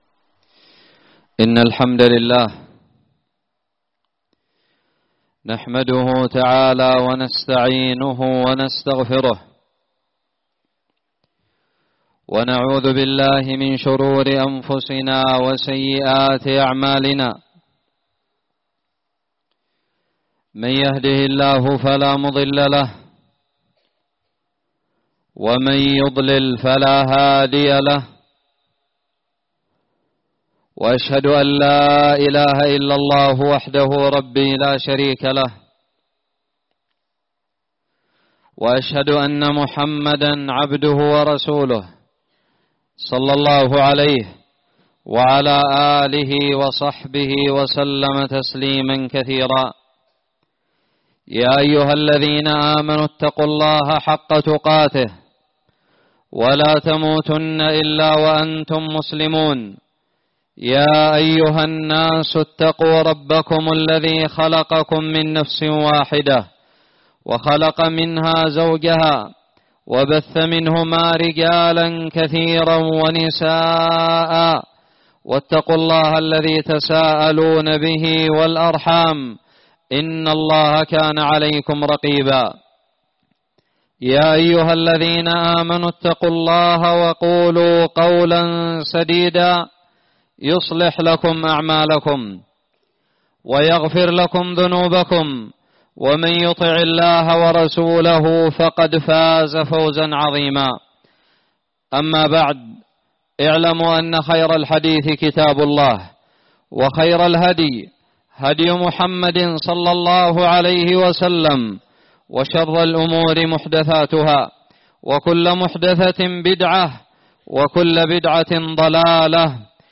خطب الجمعة
ألقيت بدار الحديث السلفية للعلوم الشرعية بالضالع في 29 شوال 1439هــ